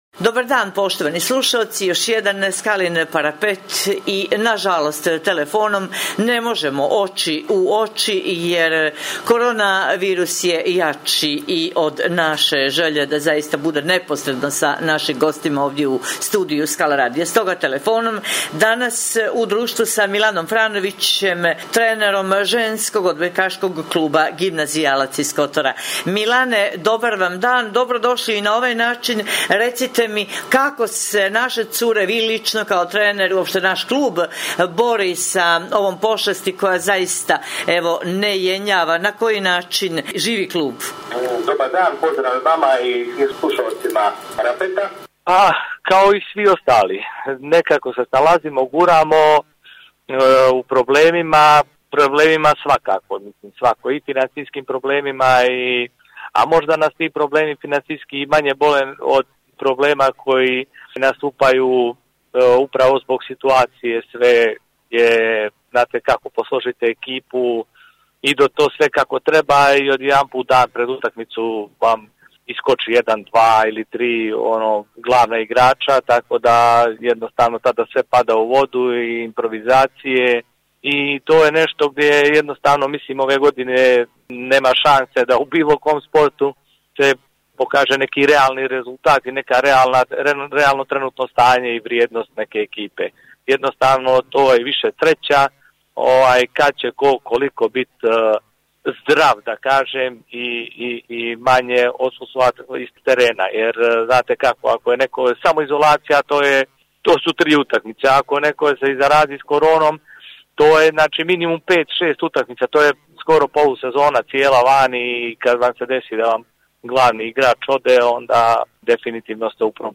Poštujući pravila nadležnih zbog epidemije korona virusa Skala radio će emisiju “Skalin parapet” emitovati u izmijenjenoj formi i u skraćenom trajanju, budući da nema gostovanja u studiju Skala radija do daljnjeg.
Stoga ćemo razgovore obavljati posredstvom elektronske komunikacije i telefonom, kako bi javnost bila pravovremeno informisana o svemu što cijenimo aktuelnim, preventivnim i edukativnim u danima kada moramo biti doma.